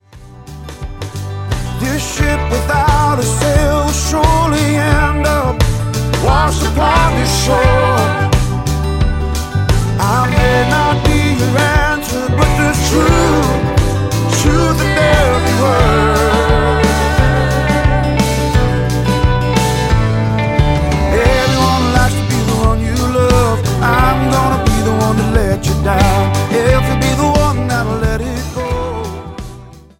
a limitless howl